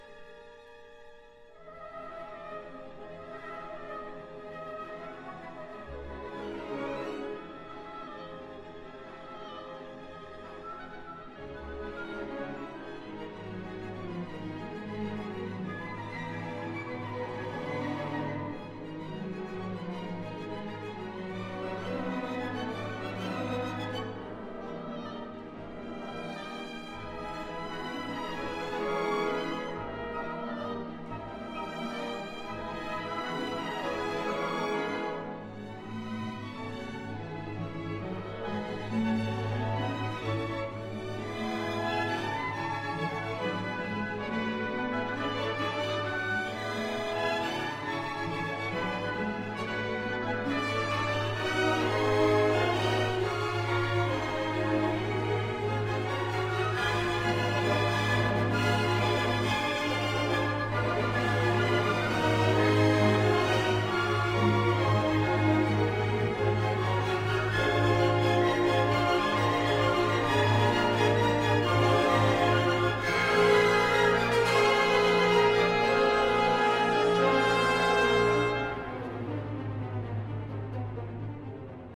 This is the Debussy La Mer Violin Excerpt from Reh. 33 until 39.
Carlo Maria Giulini, Concertgebouw Orchestra, 1995